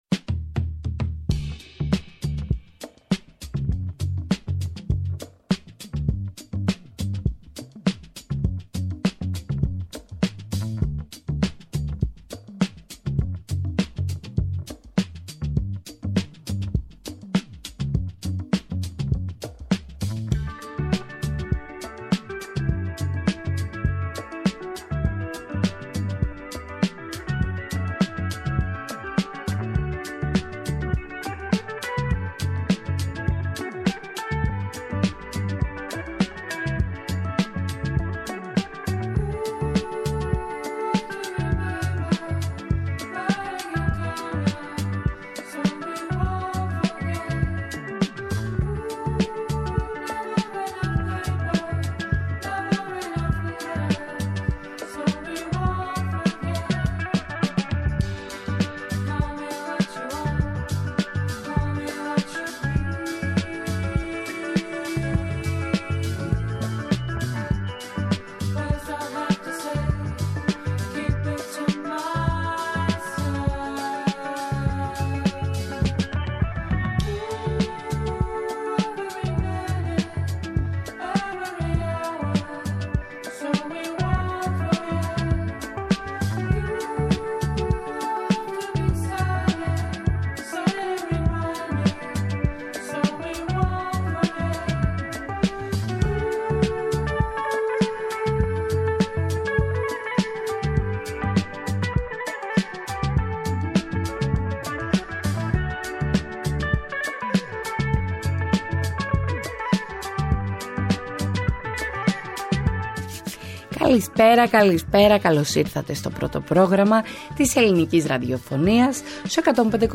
Διεθνή και εγχώρια επικαιρότητα, πολιτισμός, πρόσωπα, ιστορίες αλλά και αποτύπωση της ατζέντας της επόμενης ημέρας συνθέτουν ένα διαφορετικό είδος μαγκαζίνου με στόχο να εντοπίσουμε το θέμα της επόμενης ημέρας. Τί μας ξημερώνει αύριο; Ποιο θα είναι το γεγονός που θα κυριαρχήσει με το πρώτο φως του ηλίου; Τί θα απασχολήσει στη δημόσια σφαίρα; Ο επίλογος ενός 24ωρ